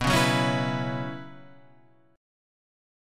Bsus2sus4 chord